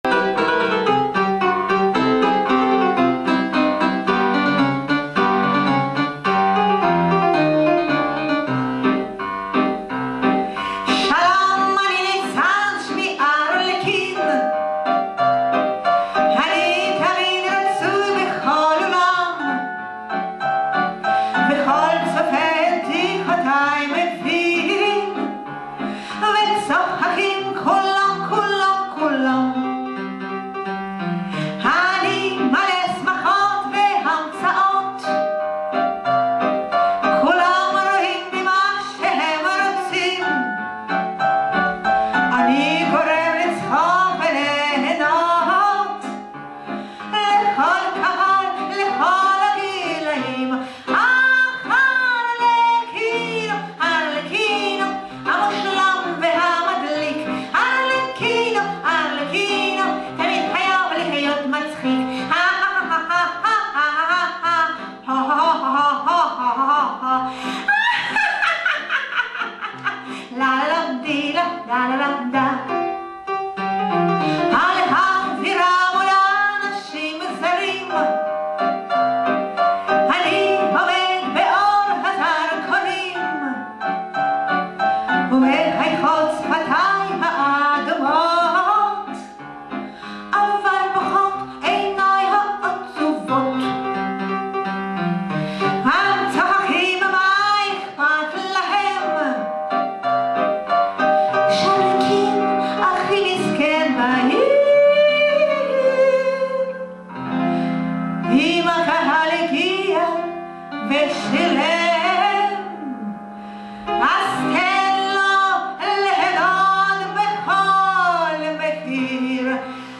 Кабацко-шансоновый такой вариантик. Самозабвенно исполненно.